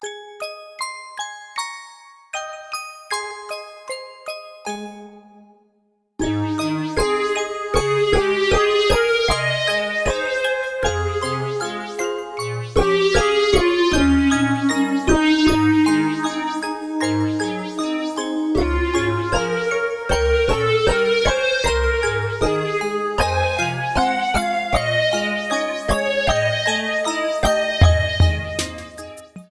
Source Ripped from the game